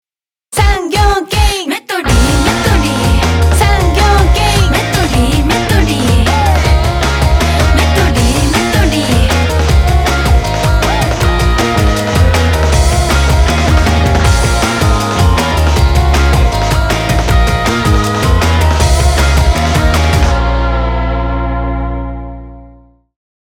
> スピード感と高揚感の中に、スタイリッシュで力強い印象が残る音に仕上げました。
SONG ELECTRO